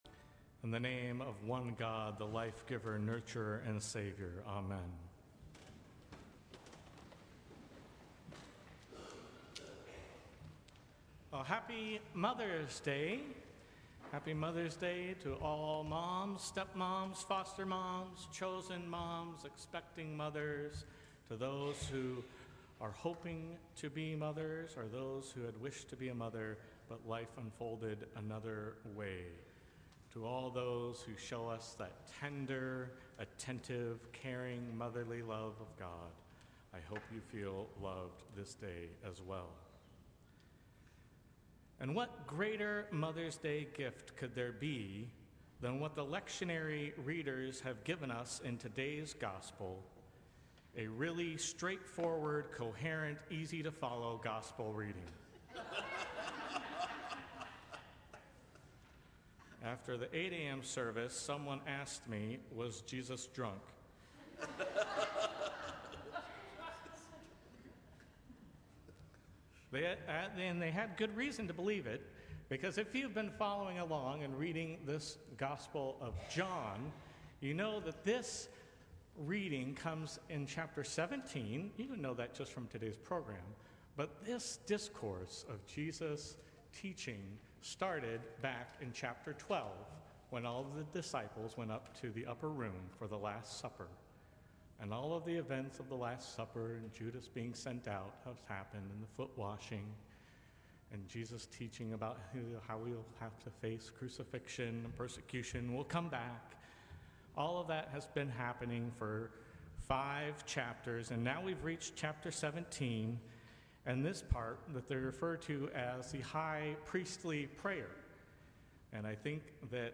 Sermons from St. Cross Episcopal Church Seventh Sunday of Easter May 19 2024 | 00:11:18 1x 00:00 / 00:11:18 Subscribe Share Apple Podcasts Spotify Overcast RSS Feed Share Link Embed